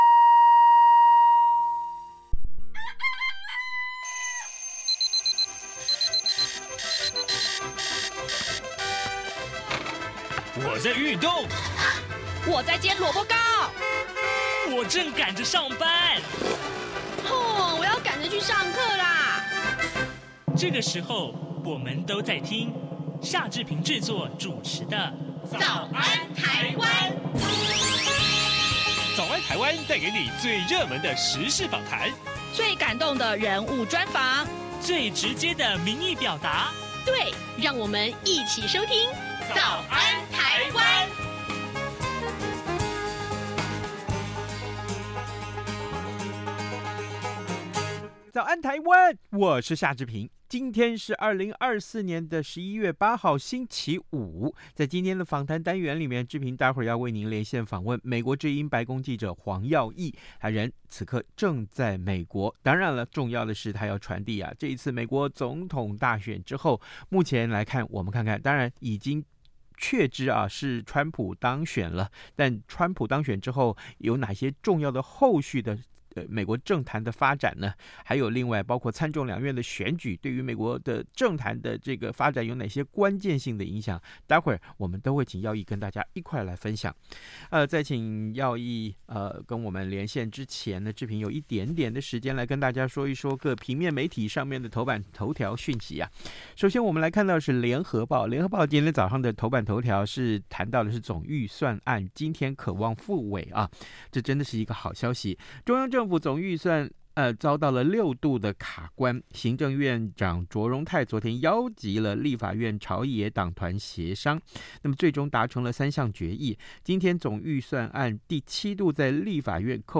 ◎美國之音每月連線：肚子不飽荷包扁扁，經濟議題掛帥！川普勝選後的焦點何在？